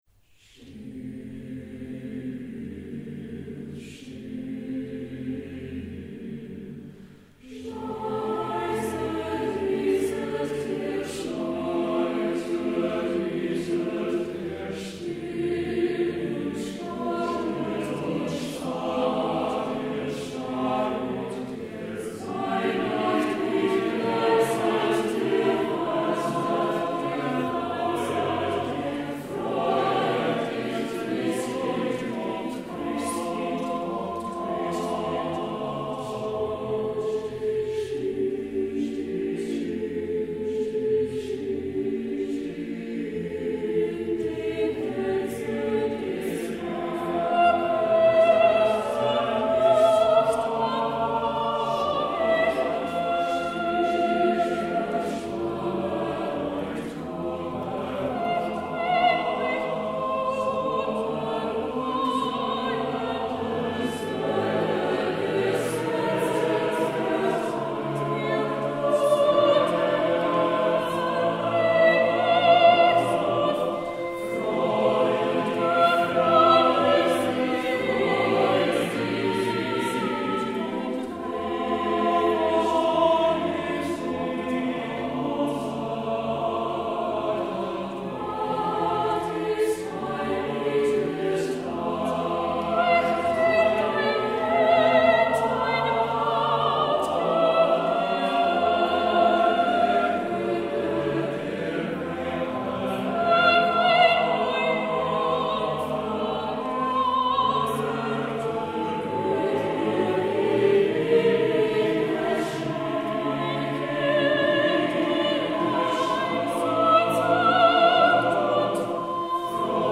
Voicing: SATBB a cappella